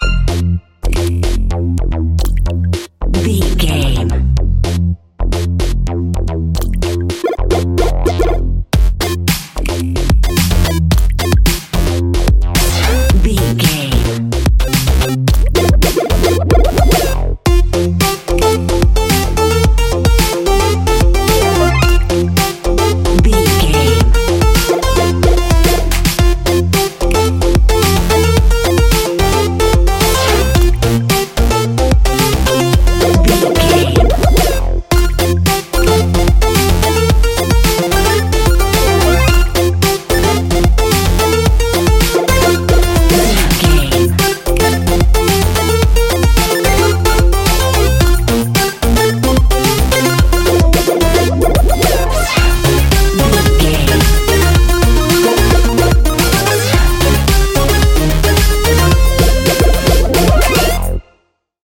positive and funny electronic music soundtrack
with 8 bit chiptune sounds and a punchy breakbeat
Ionian/Major
bouncy
energetic
groovy
drum machine